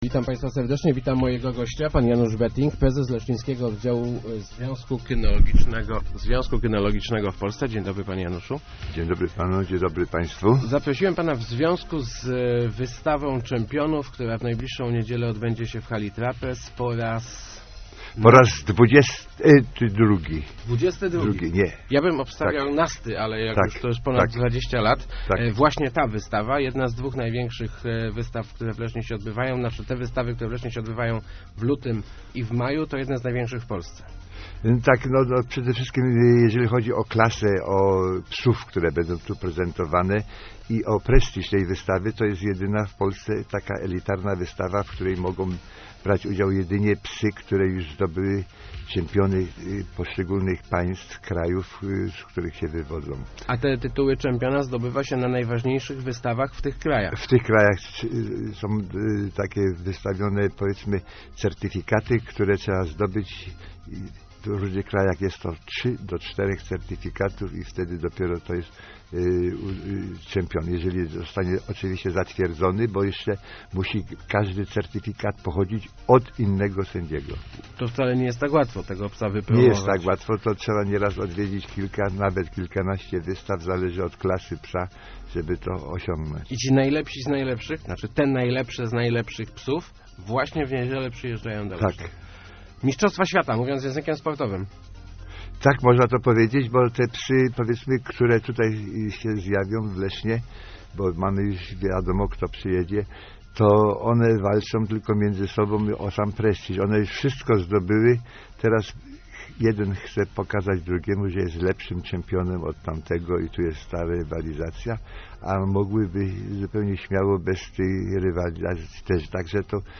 Start arrow Rozmowy Elki arrow Czempiony znów w Lesznie